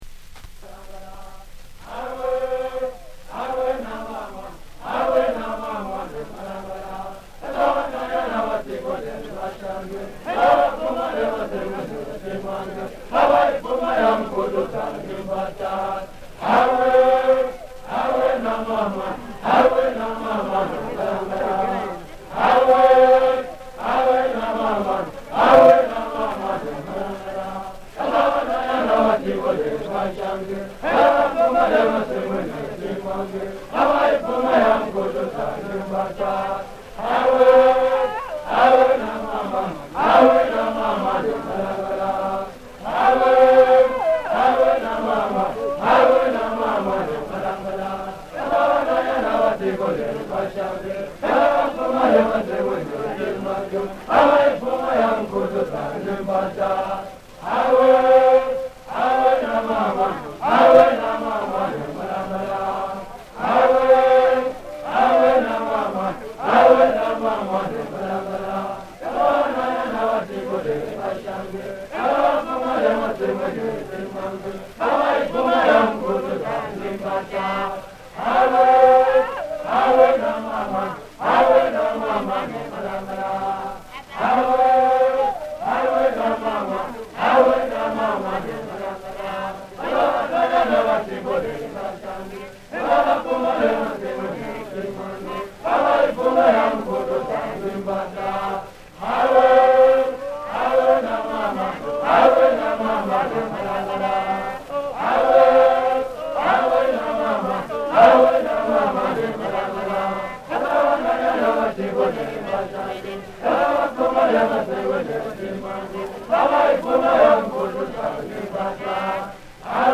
recorded 1930-06-29
78 rpm
(traditional)
CITY DEEP NATIVES - vocals
Though all recordings are credited to "City Deep Natives", there are at least two different performing groups over the four discs — a Shangaan vocal group on GR 17 and 18 and a Chopi timbila group on GR 15 and 16.